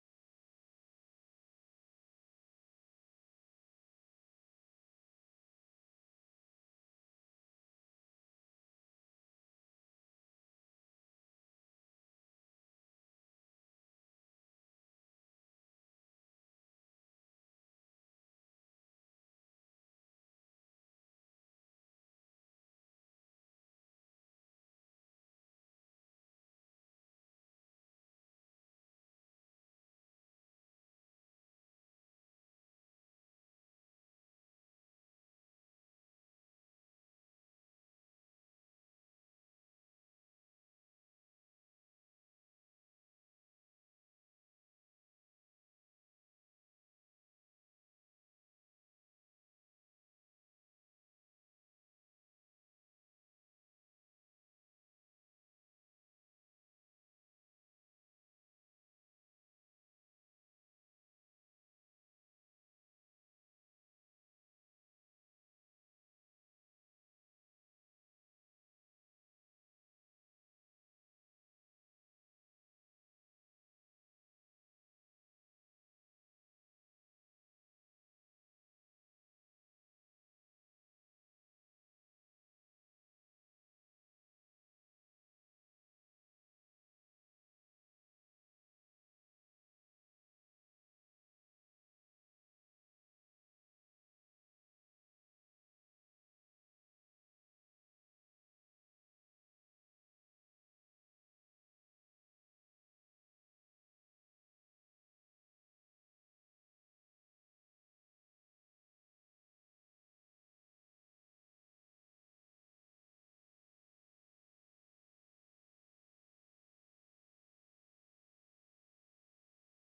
October 26 2025 Praise and Worship at FWC